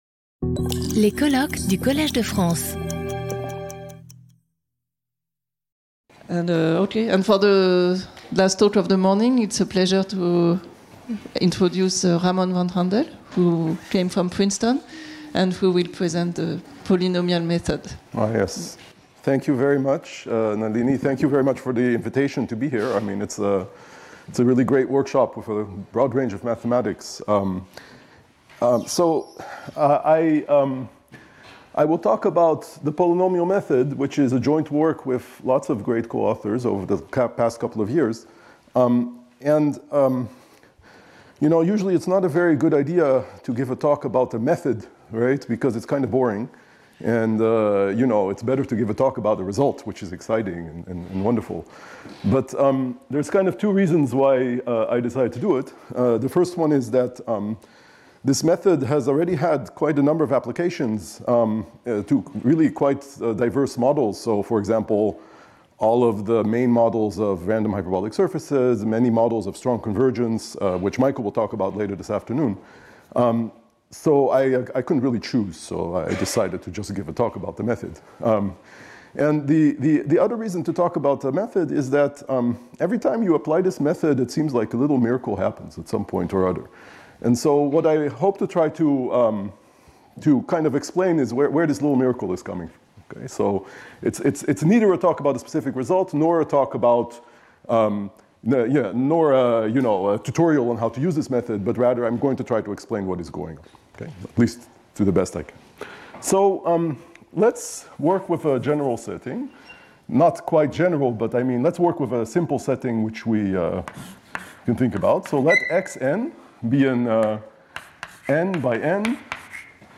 Sauter le player vidéo Youtube Écouter l'audio Télécharger l'audio Lecture audio Abstract The polynomial method is a recent approach for establishing optimal spectral gaps that has led to new progress on various problems surrounding spectral gaps of random graphs and hyperbolic surfaces, and strong convergence of group representations. My aim in this talk is to explain in a general setting how and why this method works.